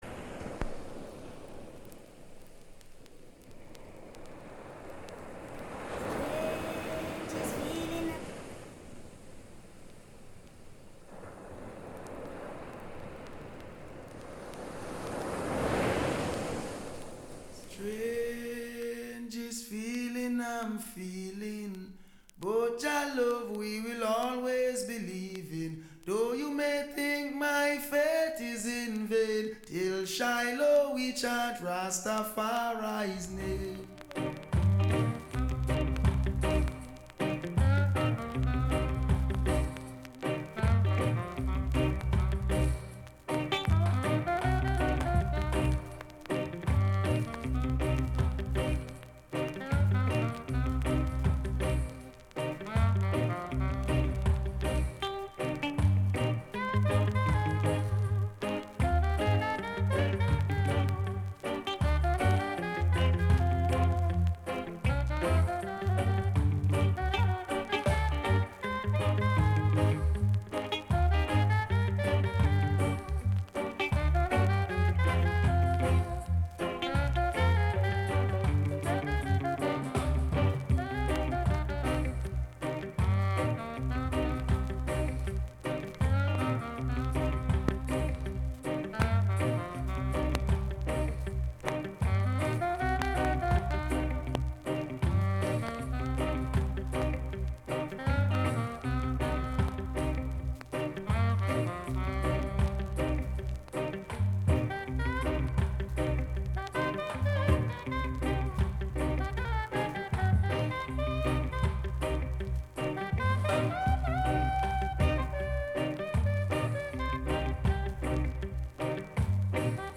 Sweet ska and rocksteady from the sixties, the deepest roots reggae from the seventies and the best dancehall from the eighties and beyond.